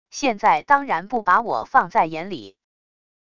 现在当然不把我放在眼里wav音频生成系统WAV Audio Player